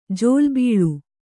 ♪ jōlbīḷu